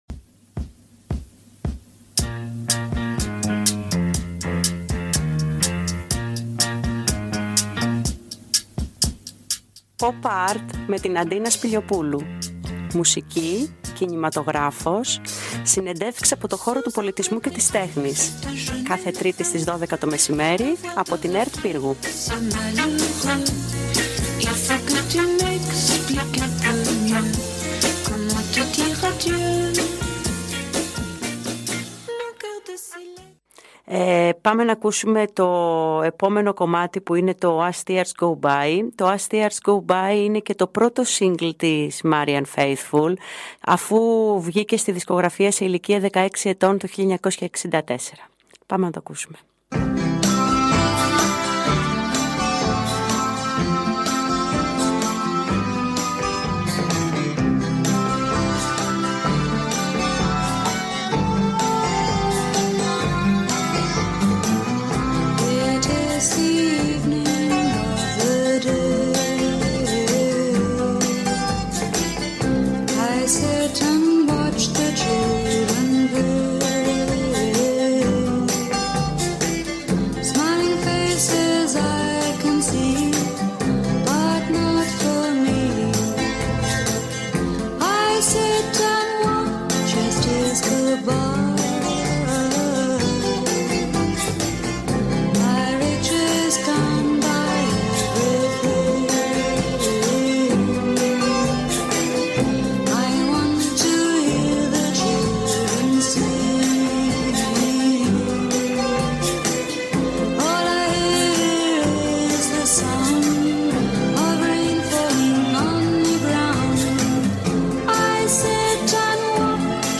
ΕΝΑ ΩΡΑΙΟ ΤΡΑΓΟΥΔΙ ΑΠΟ ΤΗΝ ΕΚΠΟΜΠΗ POP ART ΣΤΗΝ ΕΡΤ ΠΥΡΓΟΥ